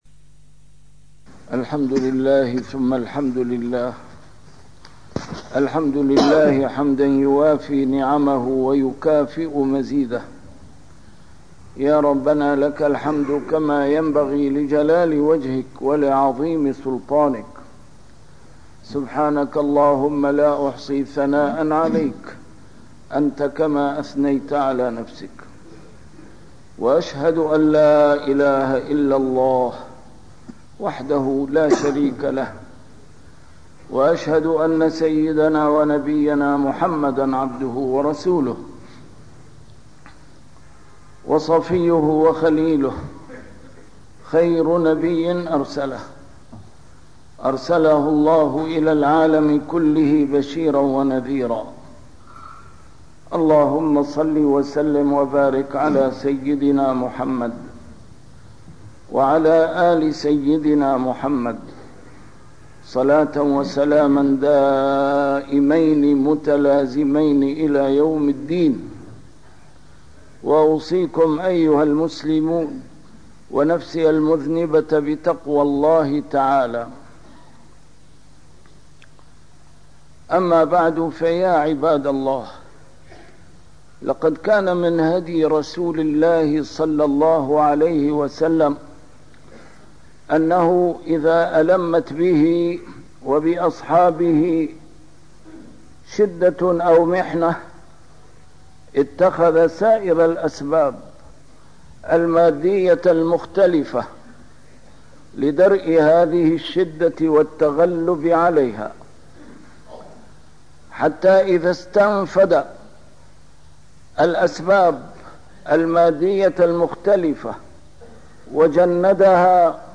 A MARTYR SCHOLAR: IMAM MUHAMMAD SAEED RAMADAN AL-BOUTI - الخطب - المنهج النبوي في مواجهة الشدائد والأزمات